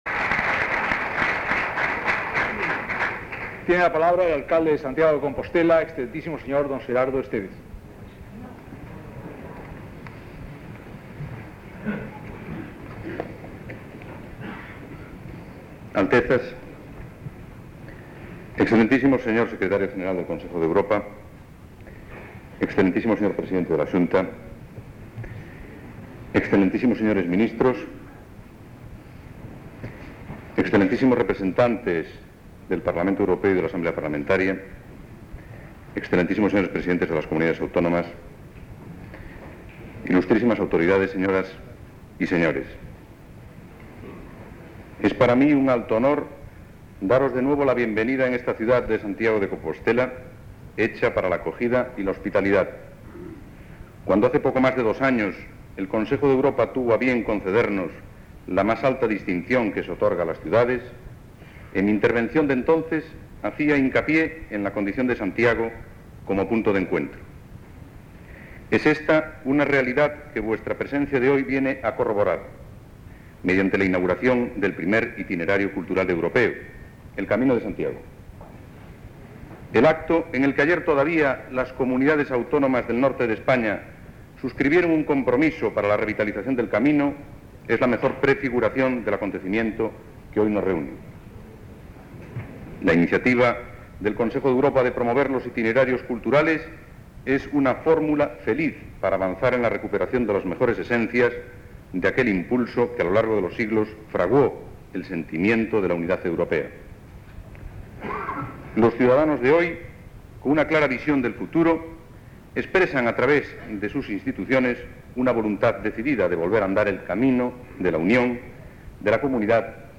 Discurso de D. Xerardo Estévez, Alcalde de Santiago de Compostela
Acto de proclamación del Camino de Santiago como Itinerario Cultural Europeo. 1987